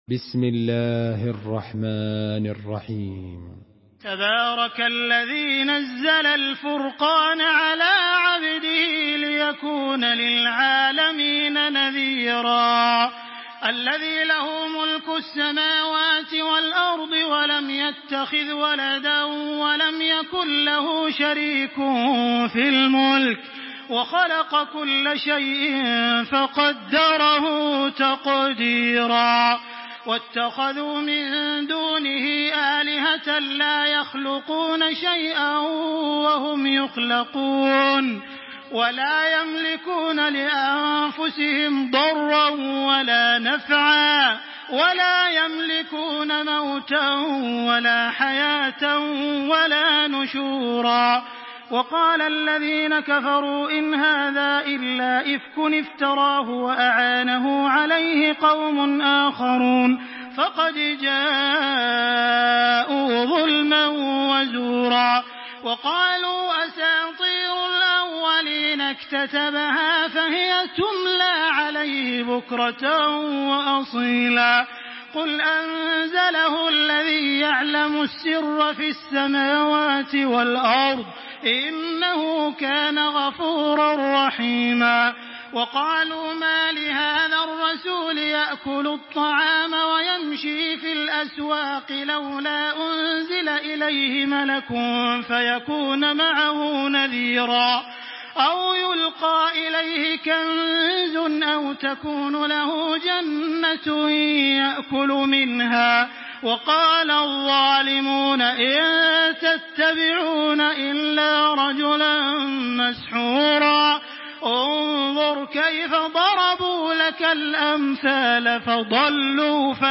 Surah الفرقان MP3 by تراويح الحرم المكي 1426 in حفص عن عاصم narration.
مرتل